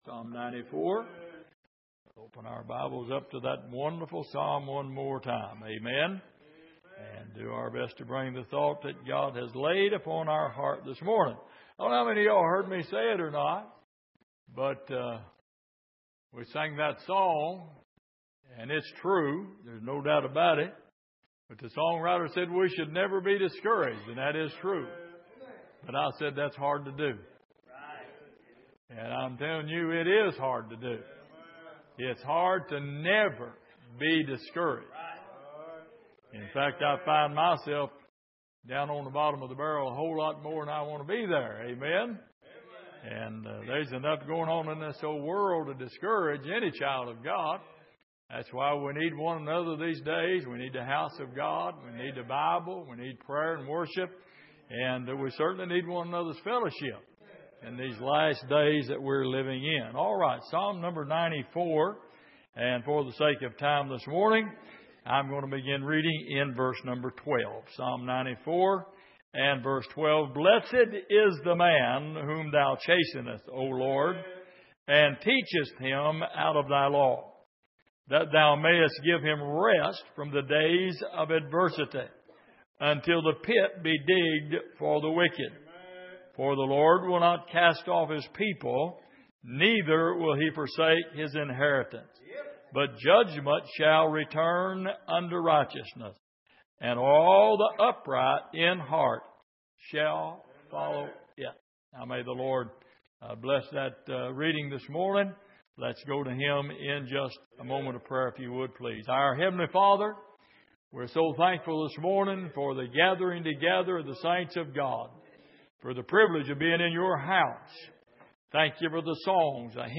Passage: Psalm 94:12-15 Service: Sunday Morning